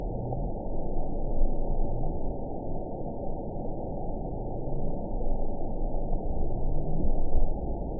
event 919768 date 01/22/24 time 14:28:44 GMT (1 year, 4 months ago) score 5.56 location TSS-AB07 detected by nrw target species NRW annotations +NRW Spectrogram: Frequency (kHz) vs. Time (s) audio not available .wav